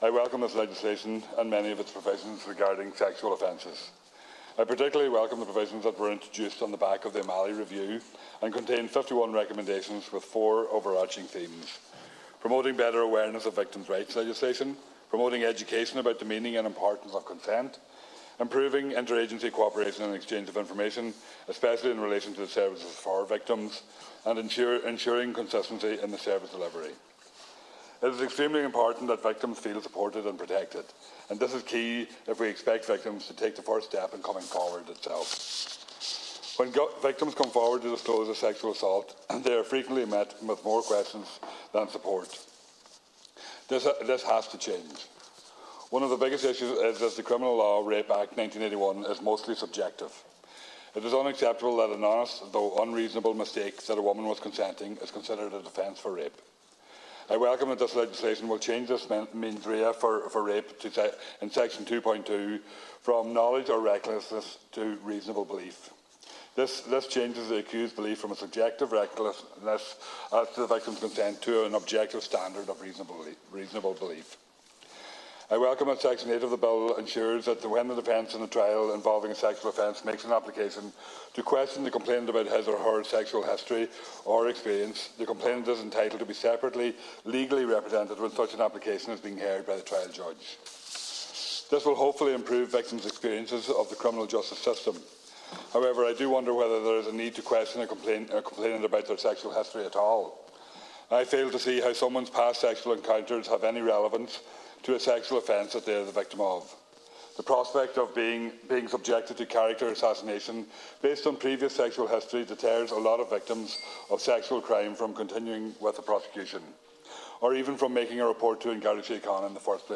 Deputy Thomas Pringle was speaking in the Dail during statements on the Criminal Law Sexual Offences and Human Trafficking Bill 2023.